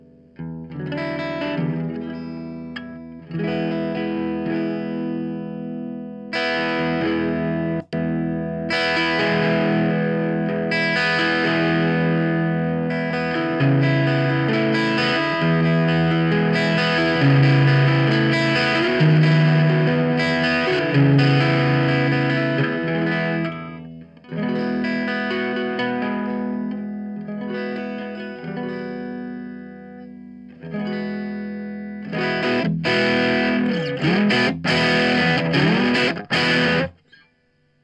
Cleanish3.mp3